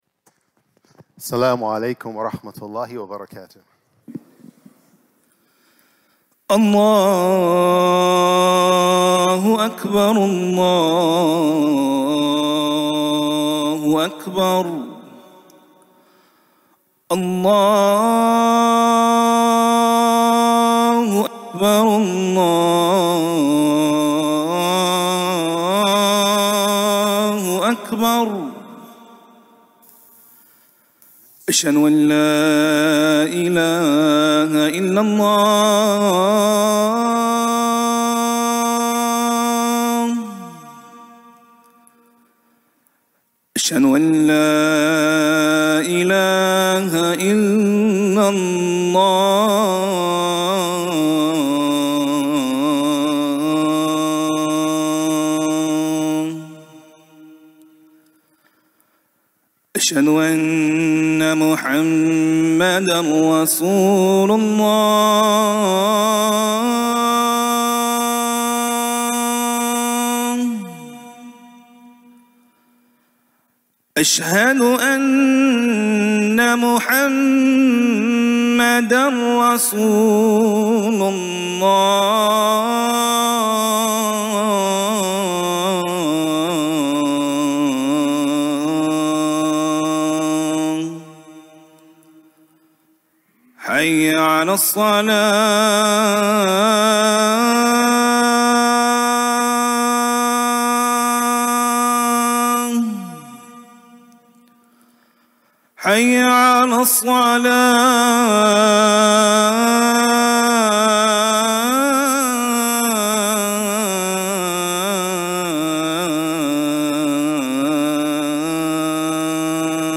Friday Khutbah - "Ramadan Transformation"